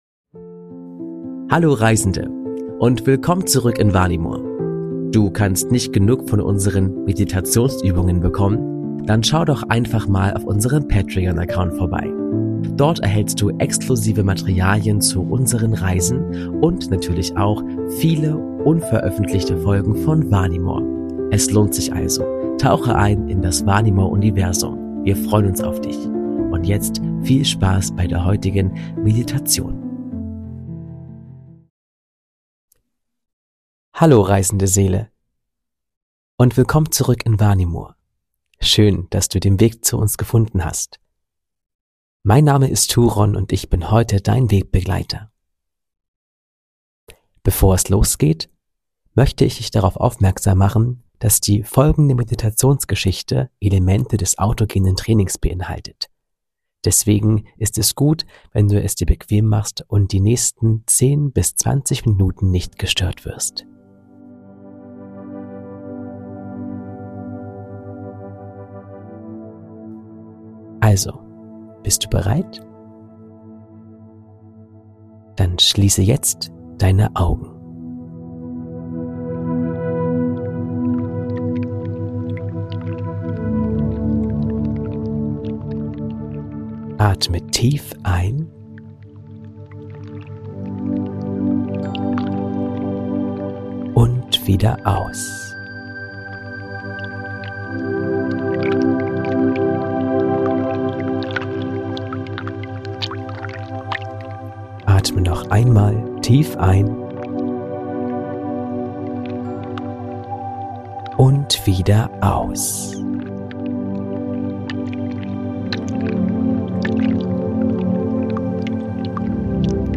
Autogenes Training/Entspannungsgeschichte: Der Baum der Erkenntnis ~ Vanimor - Seele des Friedens Podcast